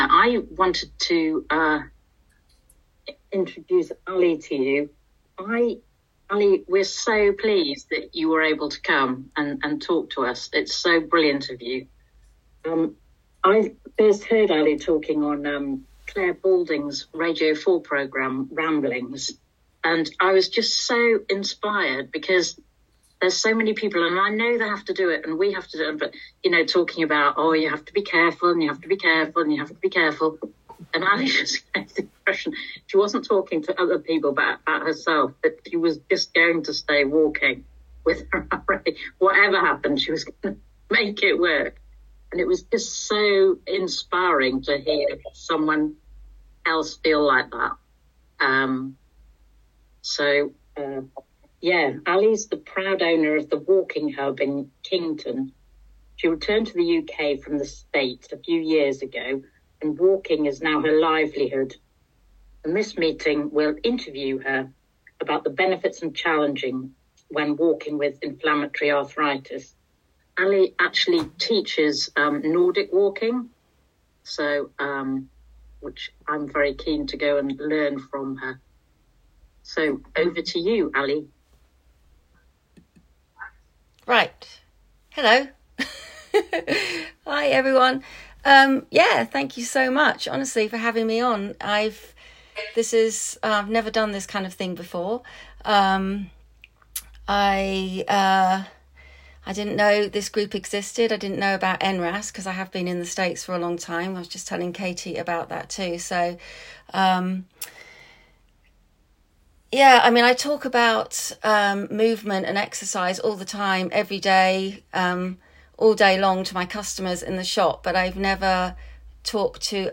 NRAS Exercise & Back to Sport Group online chat 11 July 2024